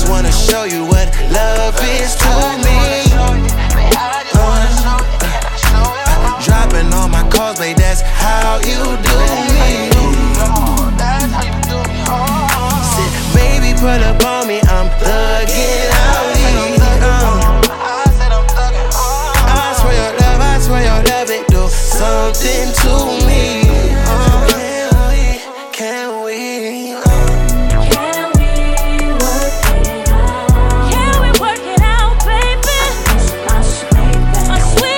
Плавные вокальные партии и чувственный бит
Глубокий соул-вокал и плотные хоровые партии
Жанр: R&B / Соул